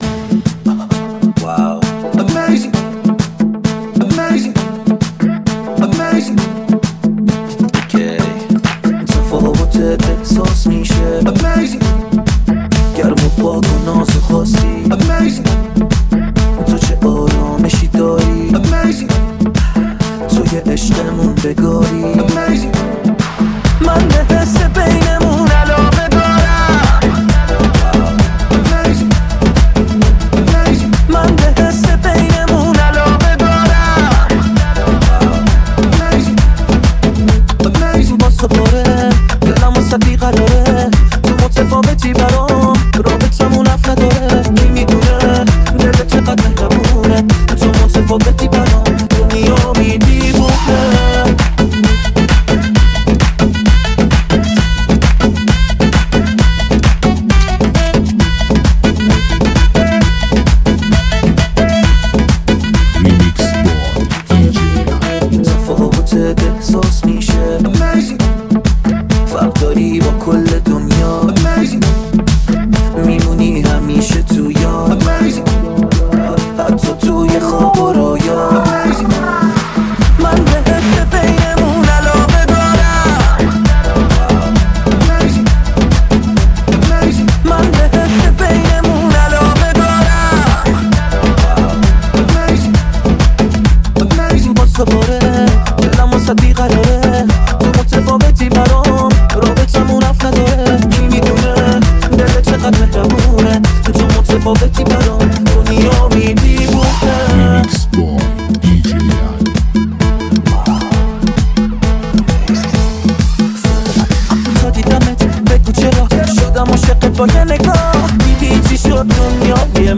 لذت بردن از موسیقی پرانرژی و بیس قوی، هم‌اکنون در سایت ما.